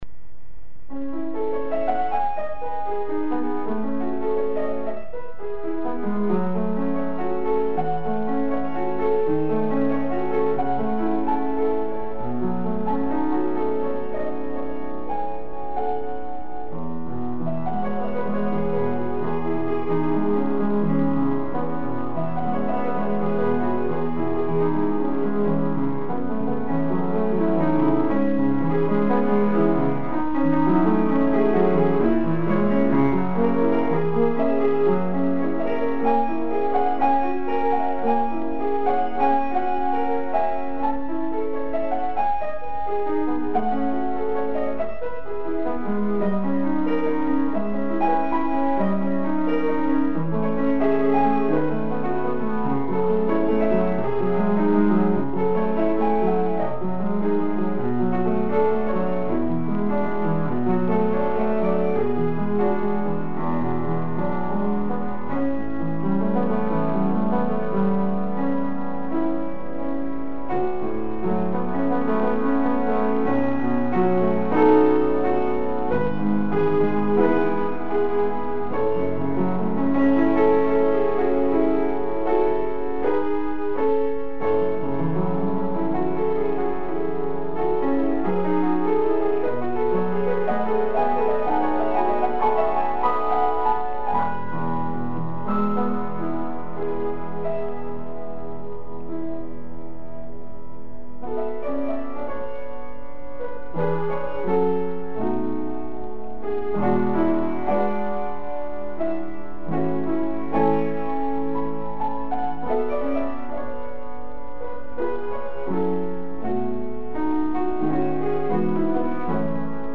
Mon piano : Roland HP-605
2-Le 3 pour 2 est bien maîtrisé (c'est l'une des difficulté de ce morceau), et tu arrives à faire un peu de rubato, en étant toujours bien calé ce qui prouve bien que tu as du le travailler dans le bon sens.
3-Il y a trop de pédale à mon avis. C'est un peu trop noyé, et un peu trop flou.
5-C'est parfois inégal sur le plan du tempo : du rubato il en faut mais pas tant, parfois cela se précipite; je pense notamment à la dernière page : je ne trouve pas utile de presser les montées autant.